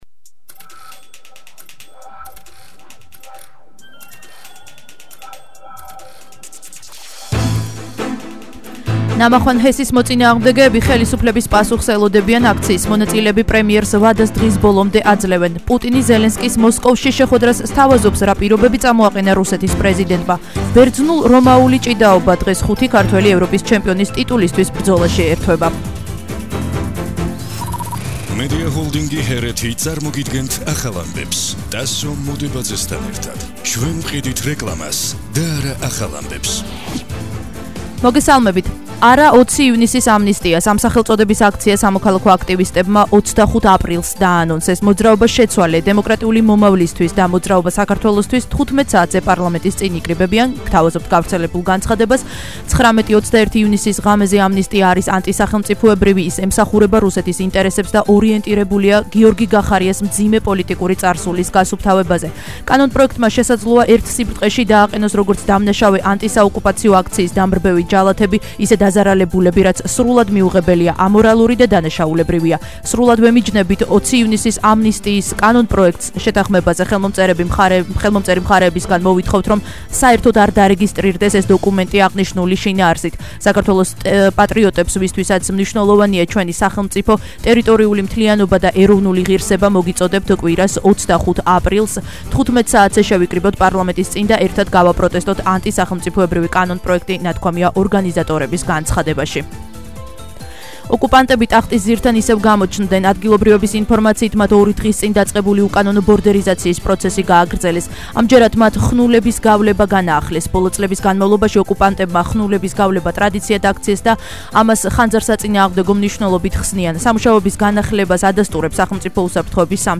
ახალი ამბები 15:00 საათზე –23/04/21 - HeretiFM